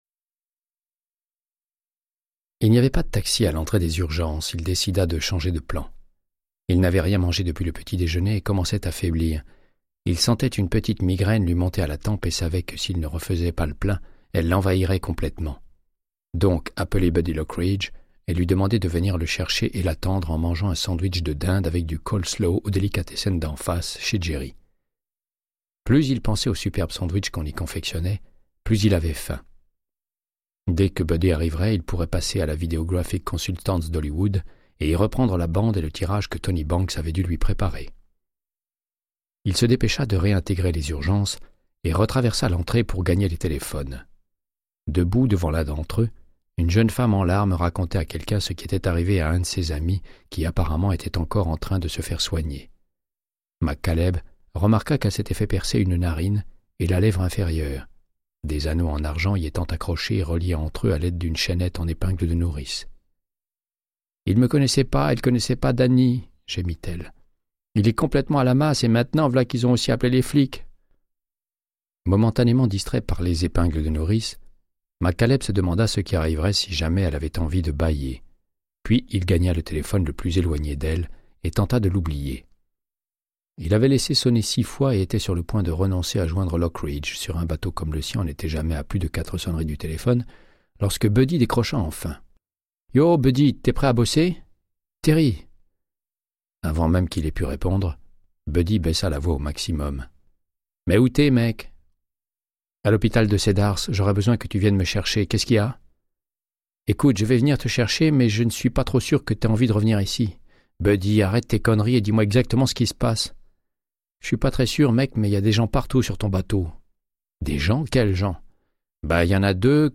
Audiobook = Créance de sang, de Michael Connelly - 123